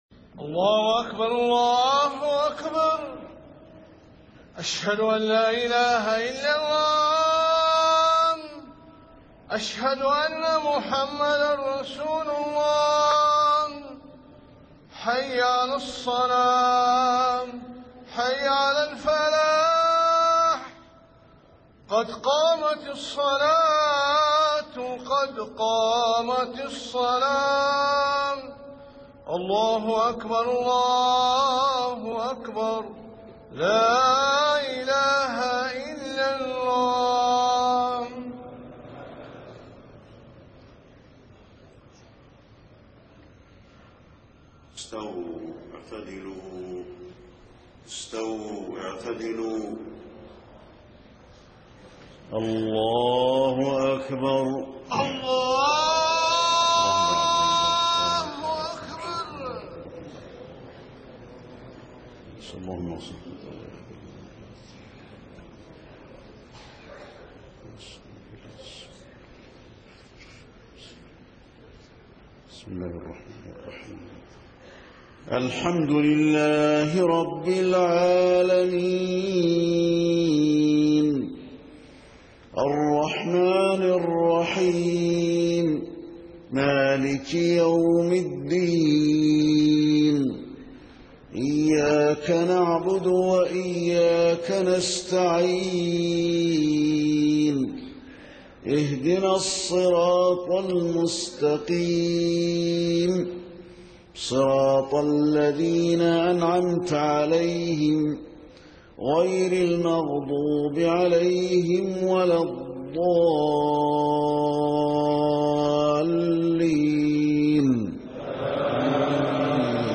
صلاة العشاء 15 محرم 1430هـ خواتيم سورة الروم 54-60 > 1430 🕌 > الفروض - تلاوات الحرمين